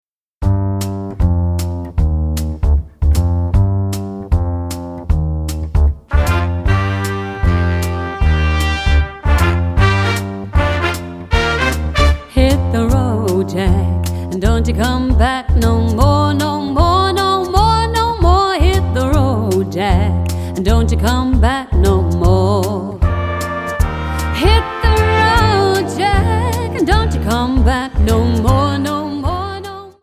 Tonart:Gm-Bbm Multifile (kein Sofortdownload.
Die besten Playbacks Instrumentals und Karaoke Versionen .